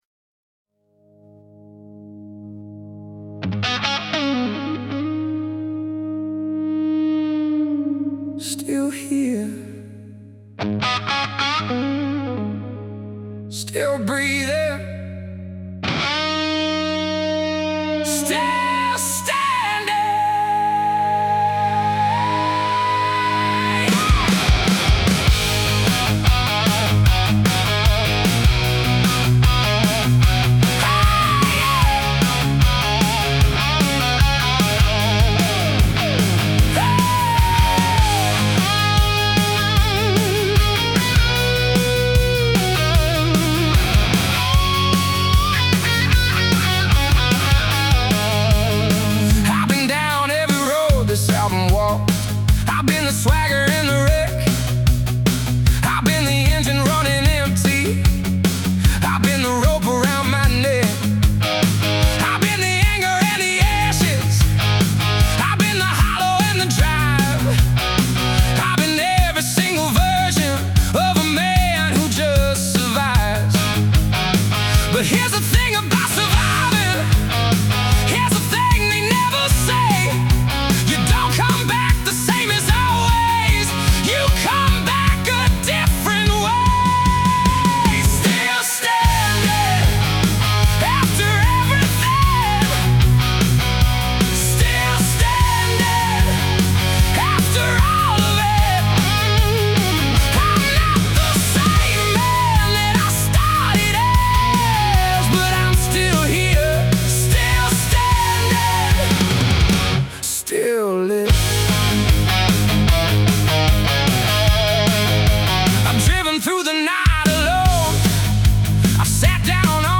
Made with Suno
hard rock, blues rock, cinematic wall-of-sound